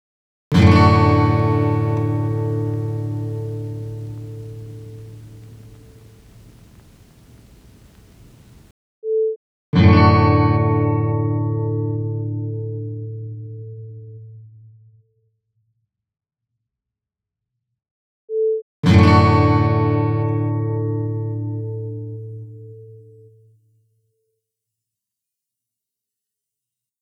Here’s a sample of the effect.
The first sound is the original unprocessed sound. It is followed by a “cleaned” copy using the Noise Removal effect in Audacity 1.3.13, and finally a copy of the original that has been processed with the Noise Coring effect.
The sound is not so muffled, keeping some of the brightness of the original recording.
I agree, and another notable feature is that it does not have any of the metallic/bubbly artefacts that are common with Noise Removal.
The effect is specifically designed to deal with “hiss” type noise and I think that it’s showing great potential.
Even at that setting there is little if any dulling of the guitar sound.